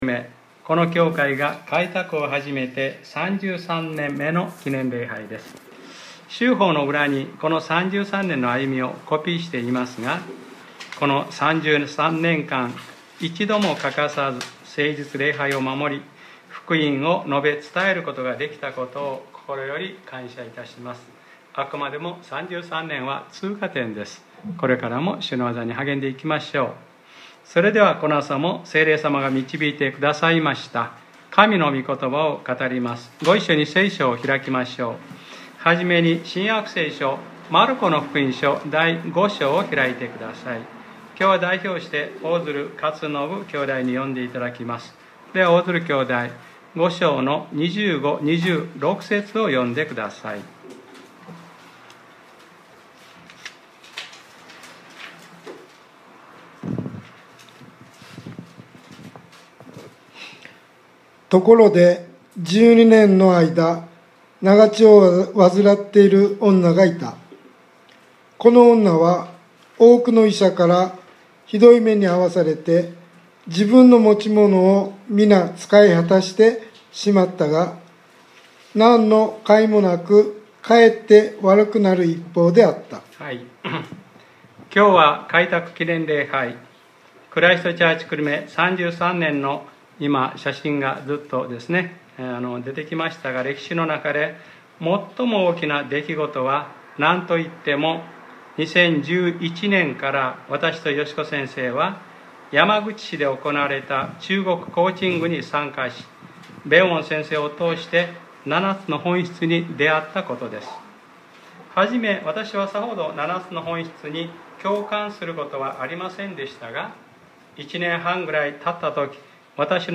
2020年10月04日（日）礼拝説教『主があなたがたのために戦われる』